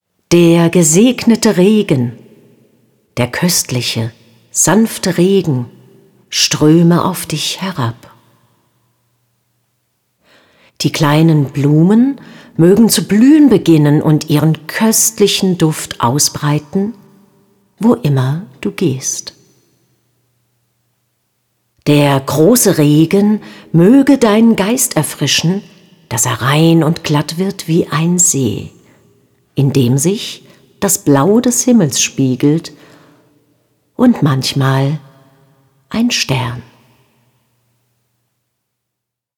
Lesungen, Gedichte und Texte